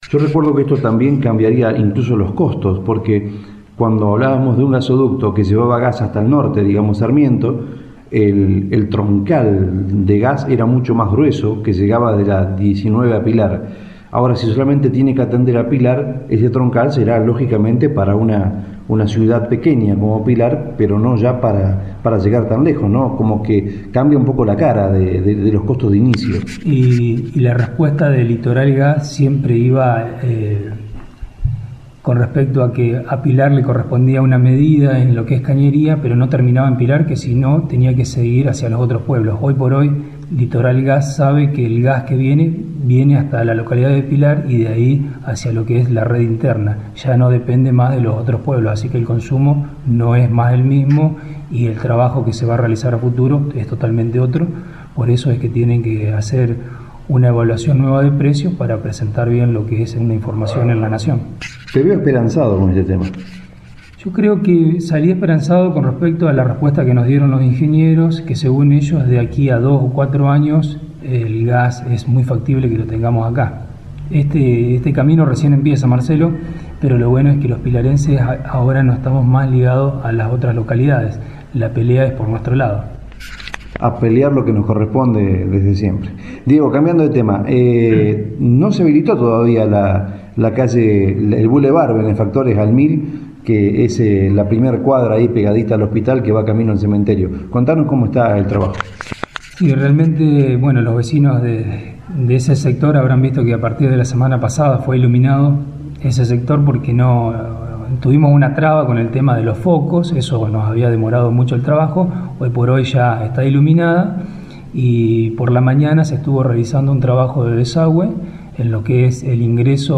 Nota a Diego Vargas Pte Comunal (parte2) - Miercoles 24 de Sep 2014